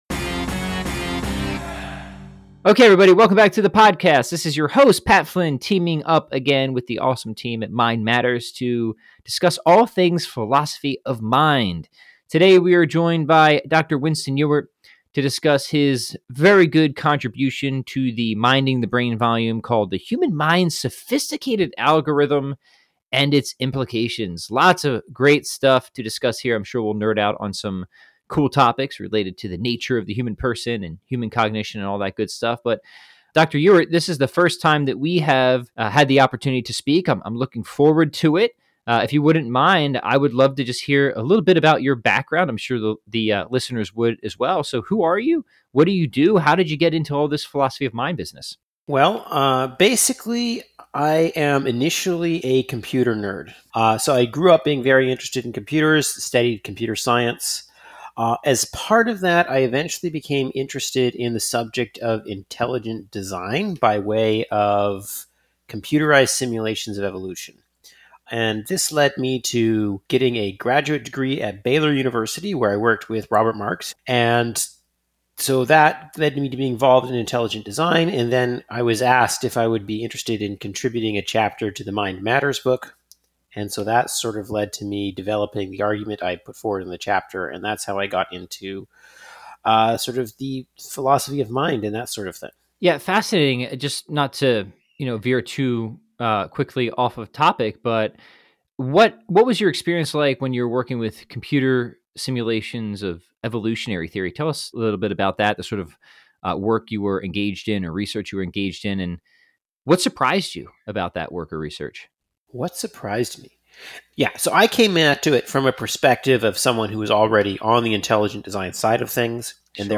This is Part 1 of a two-part conversation.